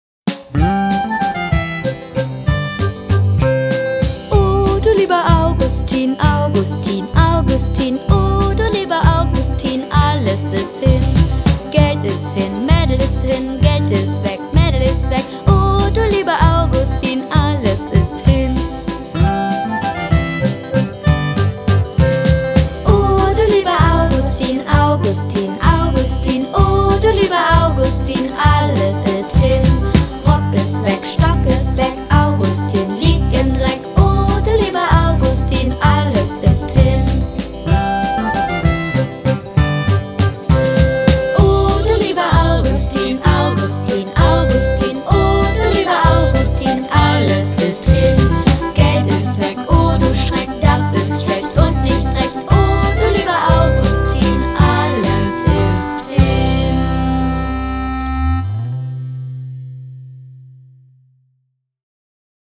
Traditionelle Lieder